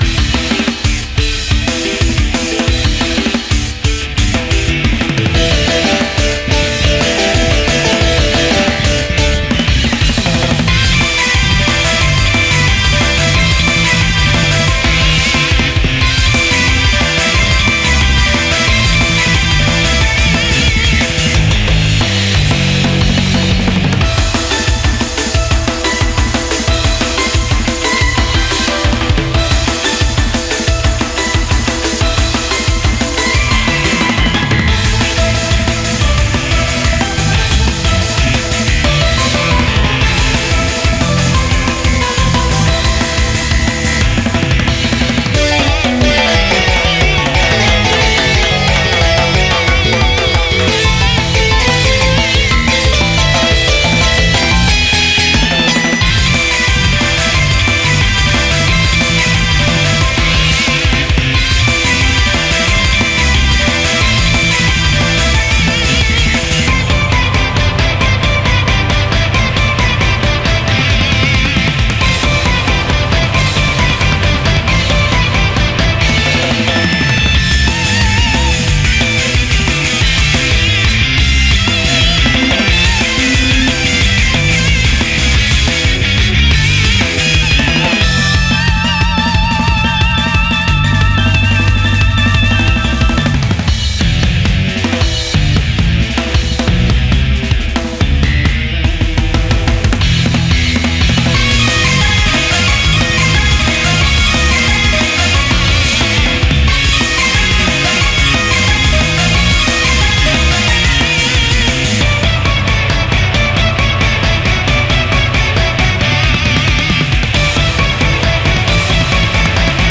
BPM42-180
Audio QualityMusic Cut